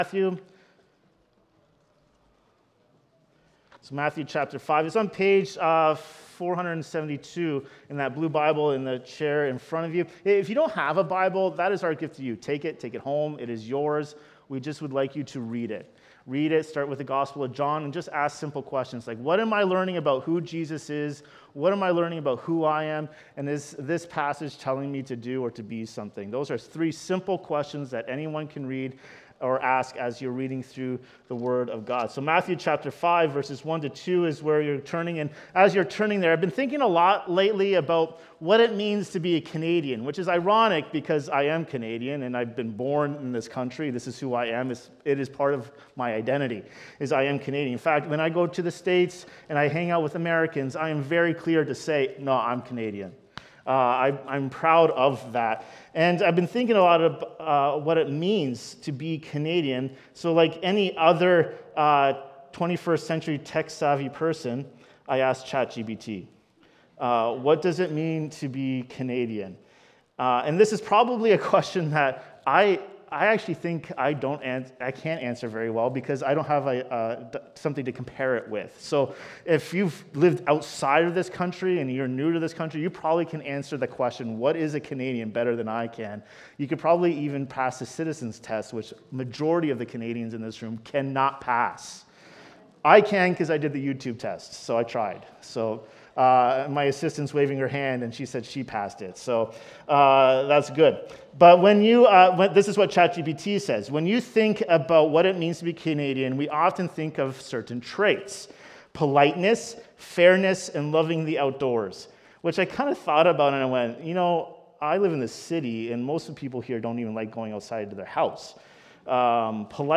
The Beatitudes: Kingdom Character in a Fallen World | Matthew 5:1–12 Sermon